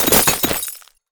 ice_spell_impact_icicle_hits3.wav